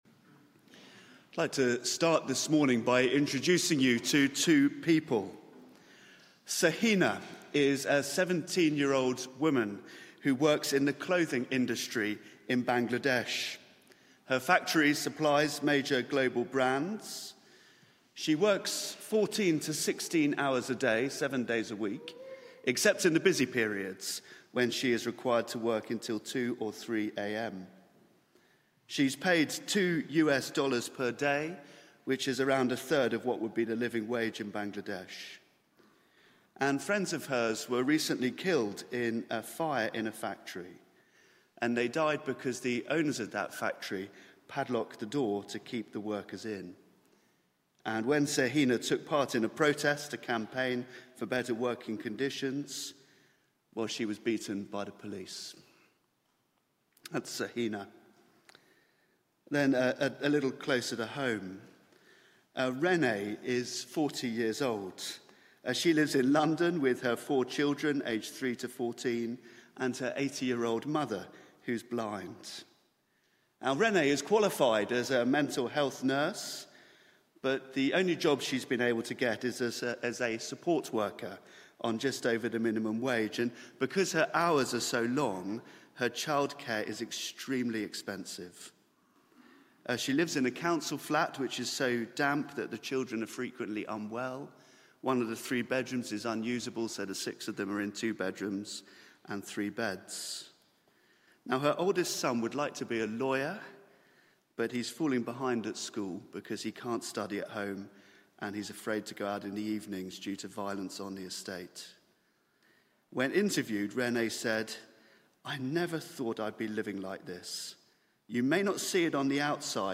Media for 9:15am Service on Sun 01st Jun 2025 09:15 Speaker
Theme: Oppression Challenged There is private media available for this event, please log in. Sermon (audio) Search the media library There are recordings here going back several years.